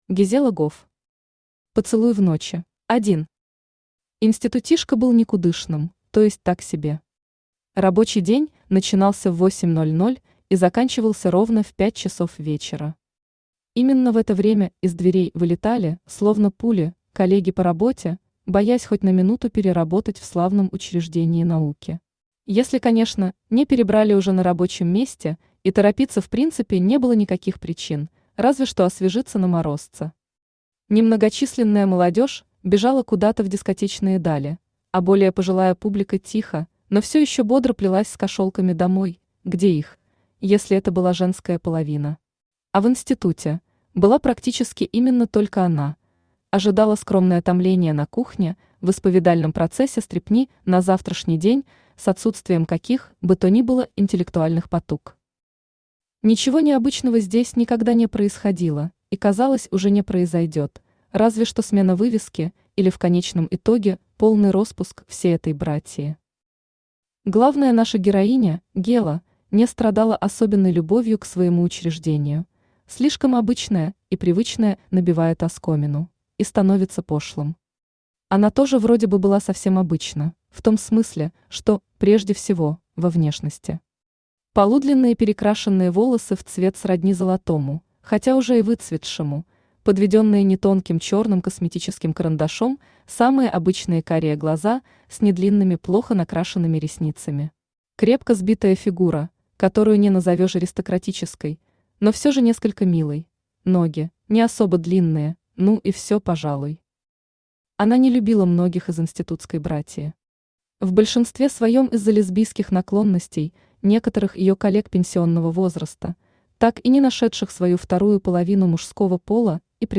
Аудиокнига Поцелуй в ночи | Библиотека аудиокниг
Aудиокнига Поцелуй в ночи Автор Гизелла Гофф Читает аудиокнигу Авточтец ЛитРес.